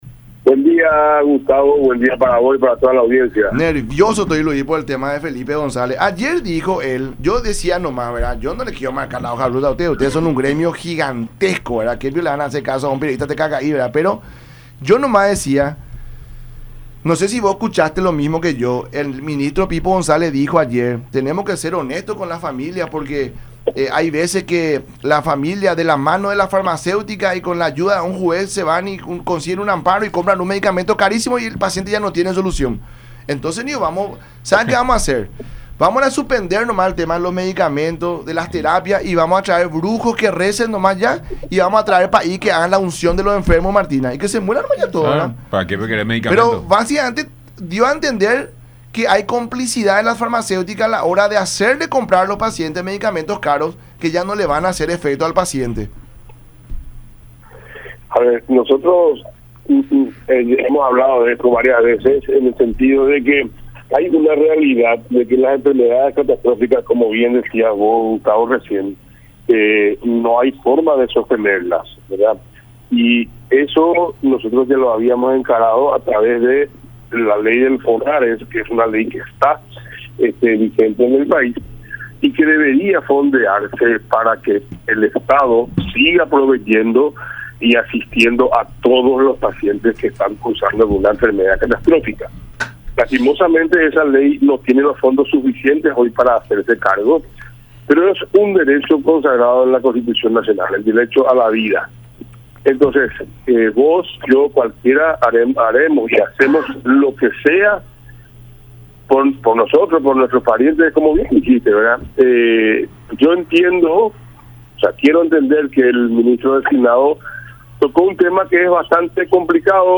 “Nosotros encaramos a través de la ley del Fonaress que debería fondearse para que el estado debería seguir proveyendo y asistiendo a los pacientes con enfermedades catastróficas, pero no hay fondos lastimosamente”, dijo en el programa “La Mañana De Unión” por Unión TV y radio La Unión.